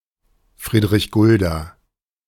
Friedrich Gulda (German: [ˌfʁiːdʁɪç ˈɡʊlda]